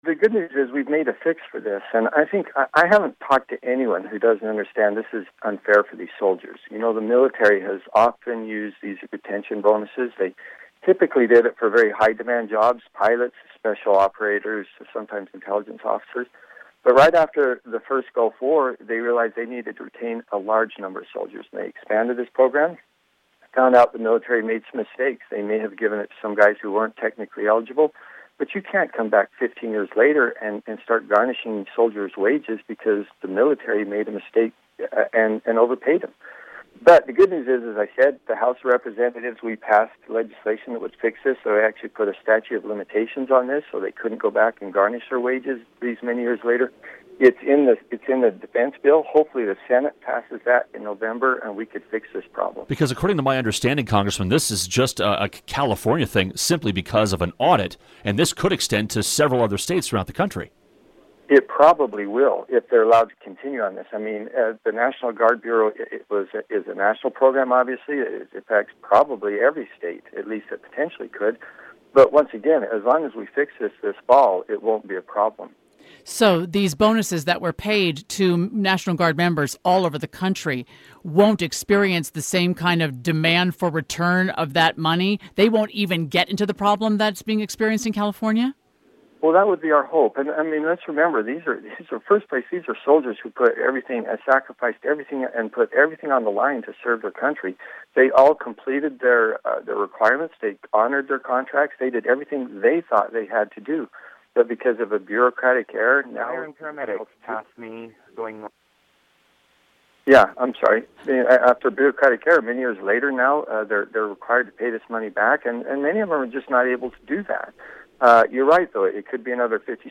Congressman Chris Stewart discussing the military bonus controversy